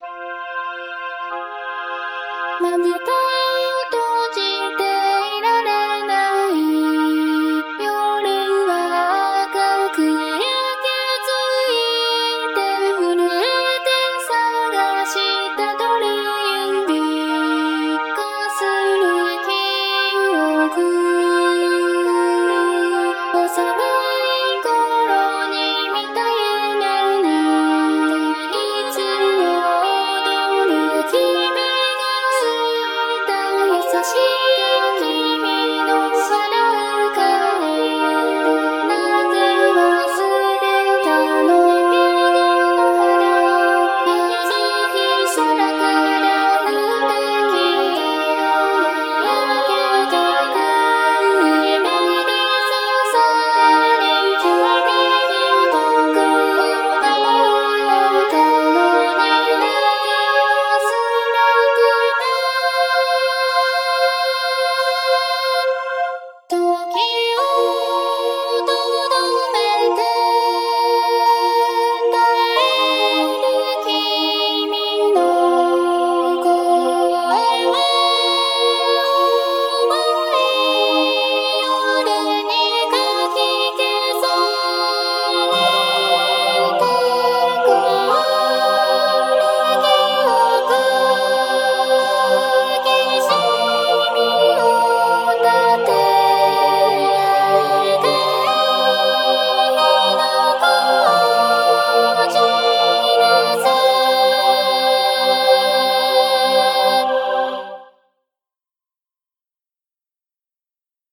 ・frogwaltzに無料のボカロみたいなやつ（UTAU + 闇音レンリ1.5）でボーカルを加えました
・カノン形式の副旋律もボーカルとして加えました。